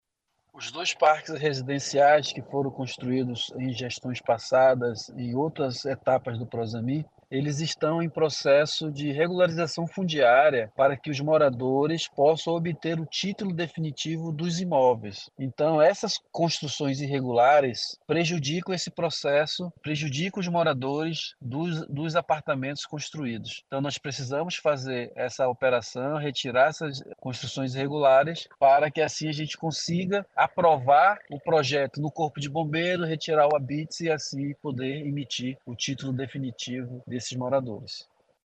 Sonora-Marcellus-Campelo-secretario-da-UGPE.mp3